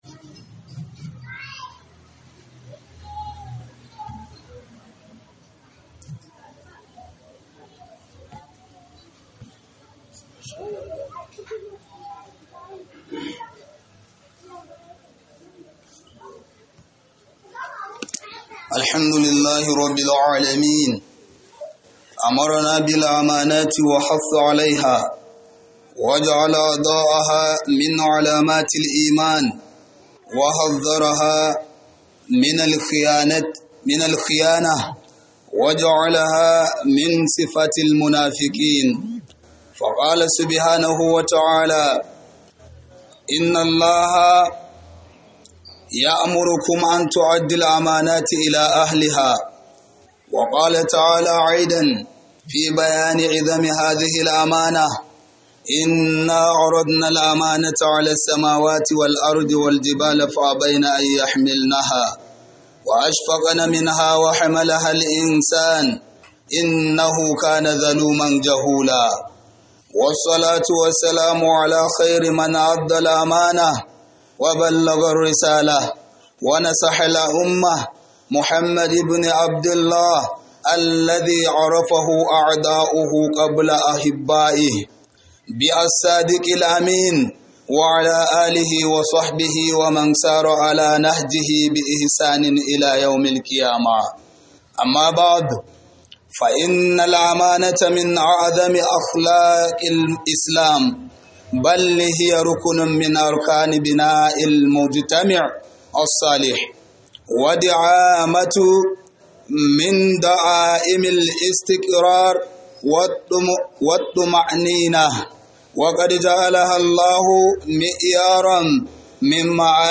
Khudubar Jibwis Burra - Rikon Amana - Khudubar Sallar Juma'a